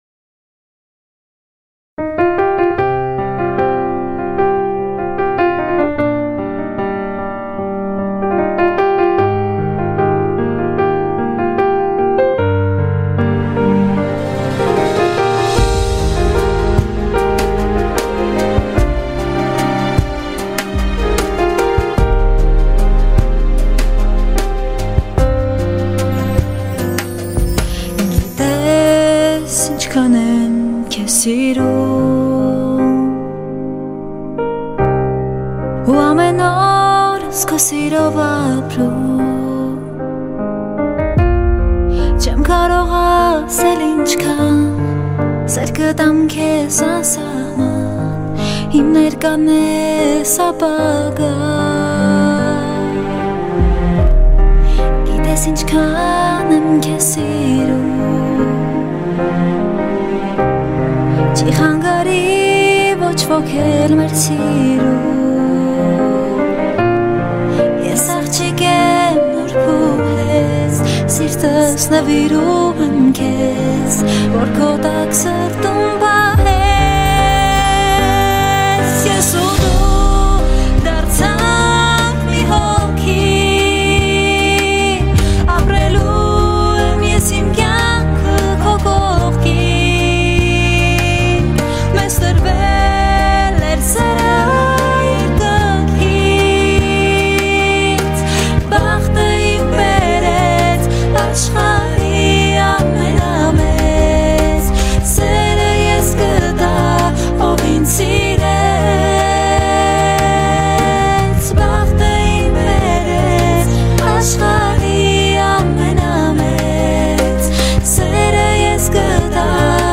Армянский